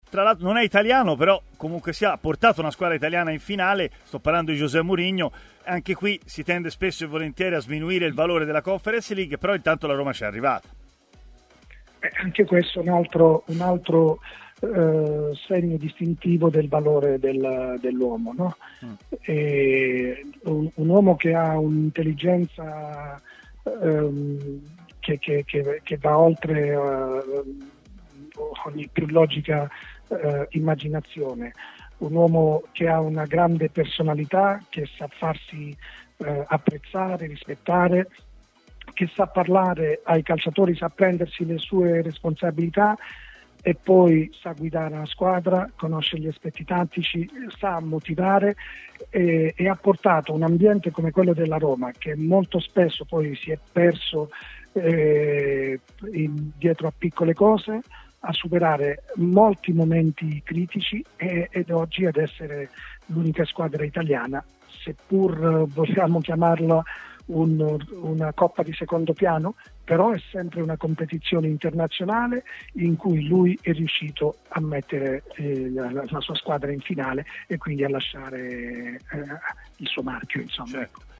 L'allenatore Luigi De Canio ha parlato a Stadio Aperto, trasmissione di TMW Radio